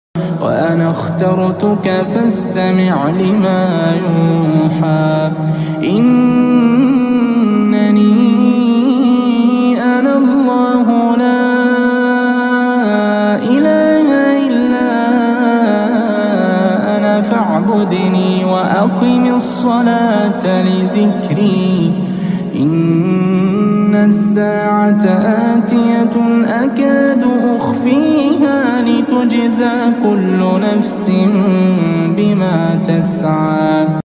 take a minute to listen to this beautiful recitation